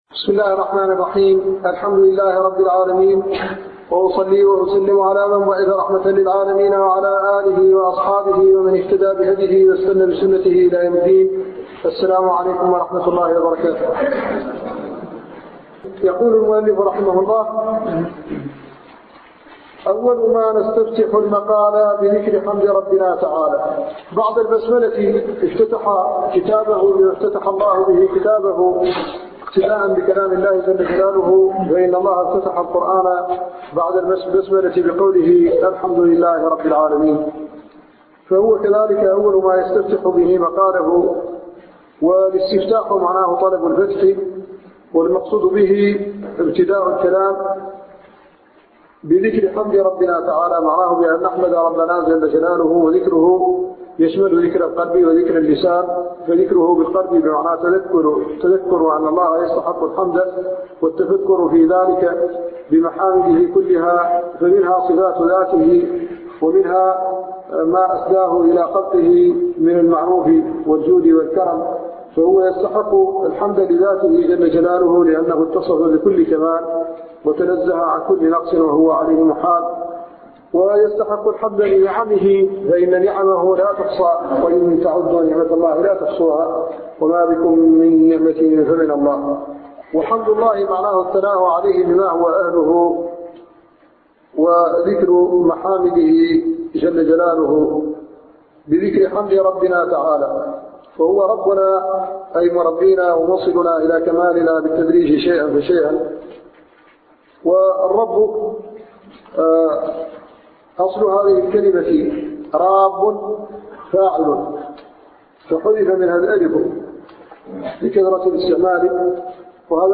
أرشيف الإسلام - ~ أرشيف صوتي لدروس وخطب ومحاضرات الشيخ محمد الحسن الددو الشنقيطي